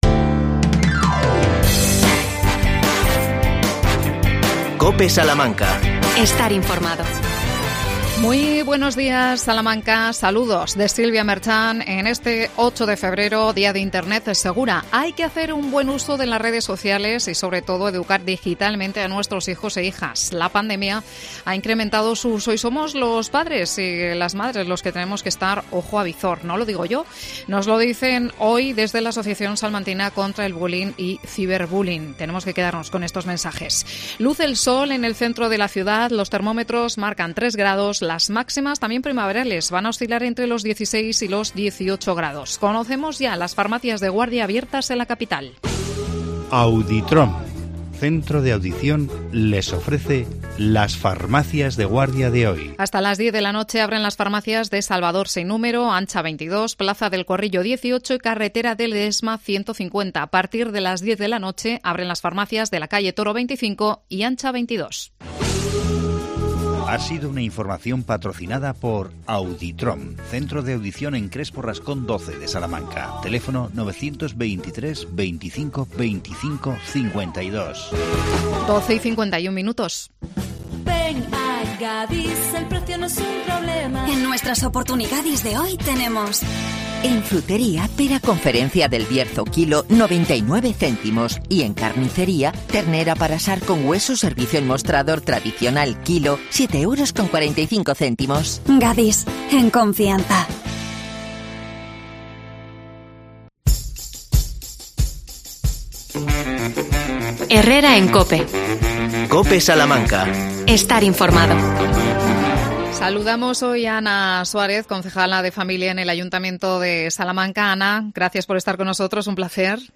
Entrevista a Ana Suárez, concejala de Familia en el Ayuntamiento de Salamanca. El tema: ludotecas municipales en Carnaval y Semana Santa.